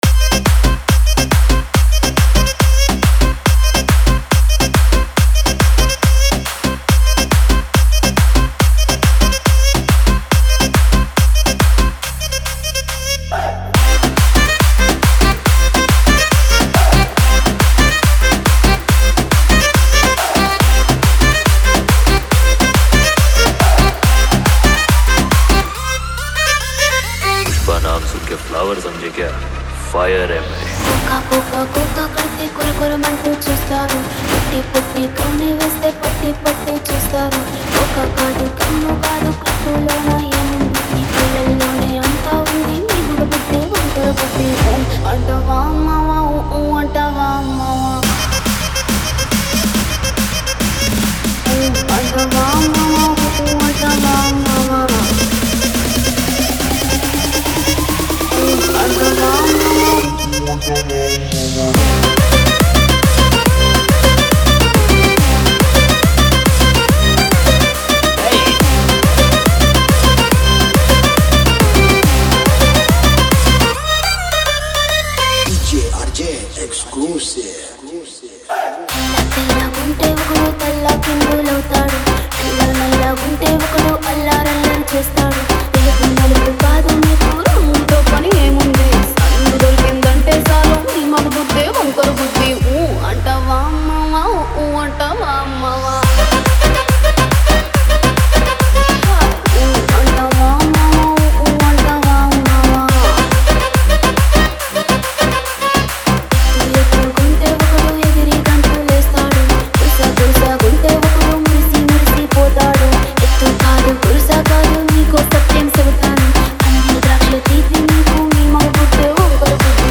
Single DJ Remix Songs